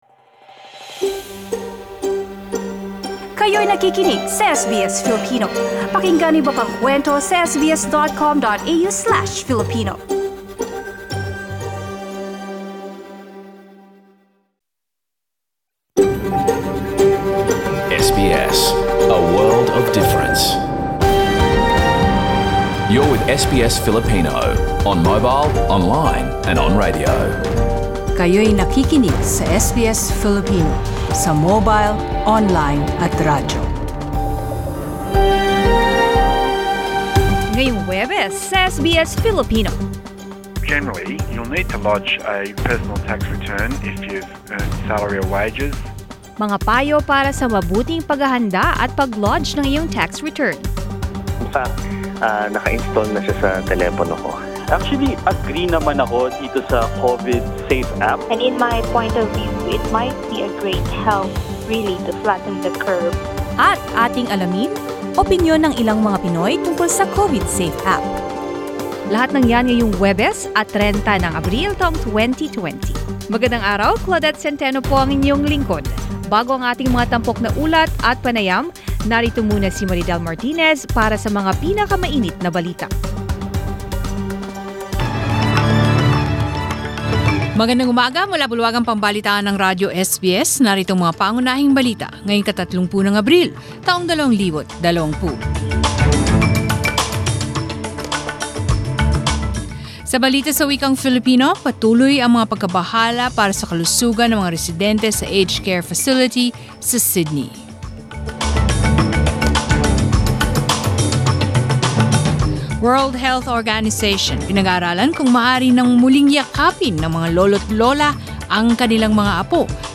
Balita ika 30 ng Abril